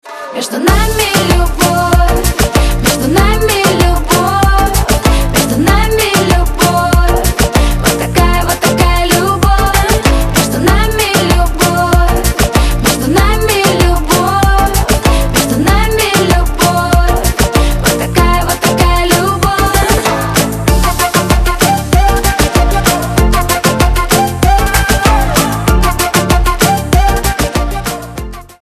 поп
позитивные
dance
солнечные
vocal